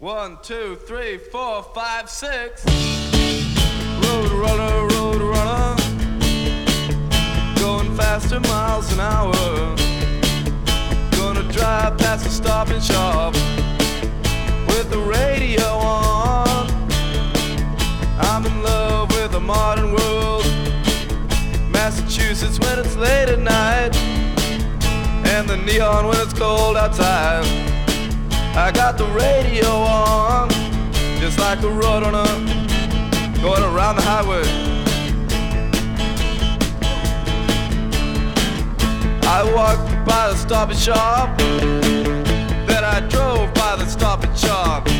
Rock, Garage　USA　12inchレコード　33rpm　Stereo